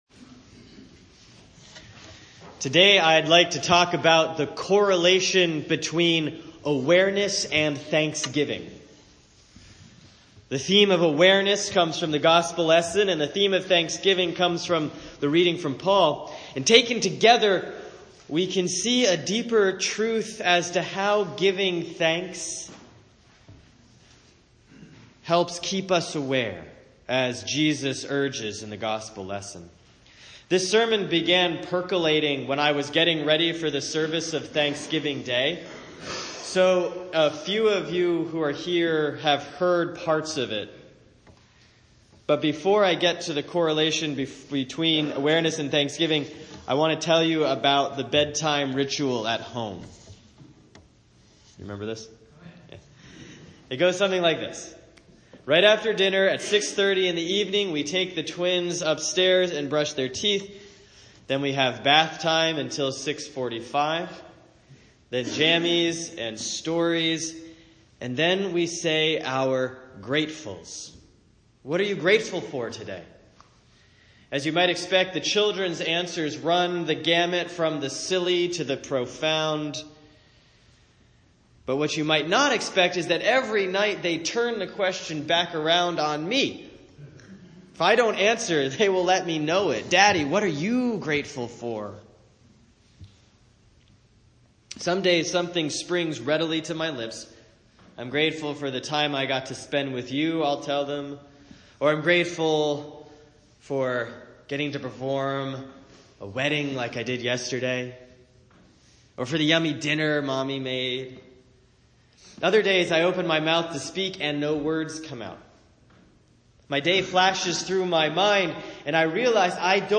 Sermon for Sunday, December 3, 2017 || Advent 1B || 1 Corinthians 1:3-9; Mark 13:24-37